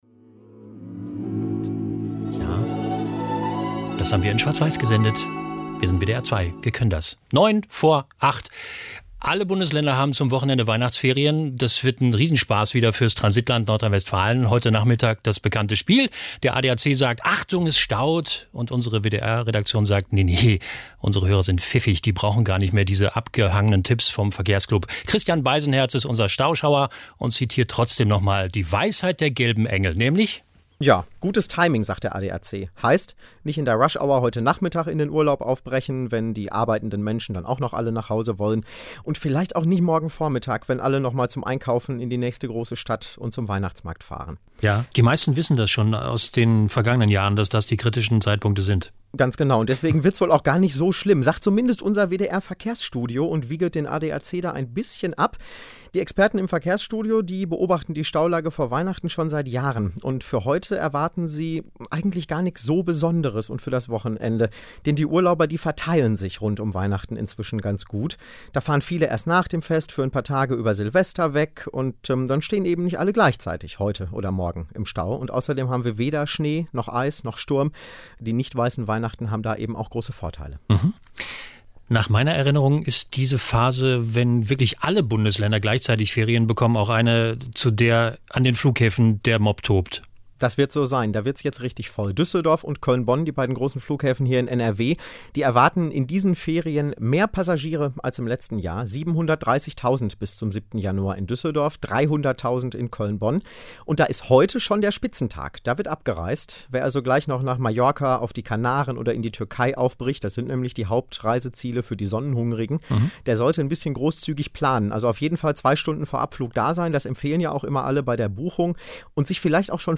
Oder Auftritte als Reporter. Zum Beispiel am 20.12.2013 in Sachen Weihnachtsreisewelle.